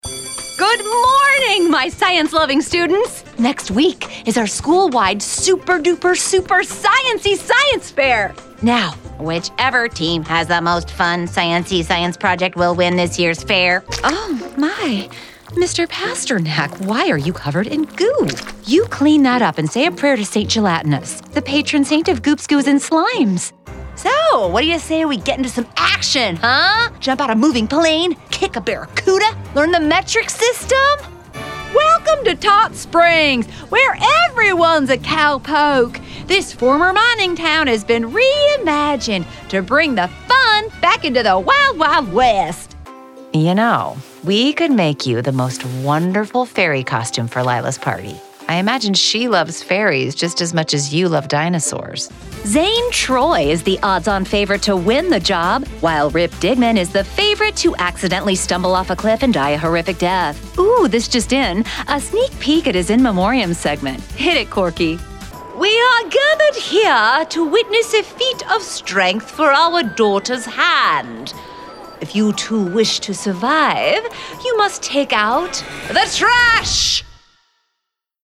Female
Yng Adult (18-29), Adult (30-50)
Hi! I have a warm, friendly, approachable voice.
I can sound professional, conversational, heartfelt, dry, witty or sardonic.
Character / Cartoon
All our voice actors have professional broadcast quality recording studios.